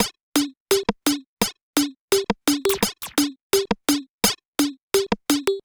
Percussion 12.wav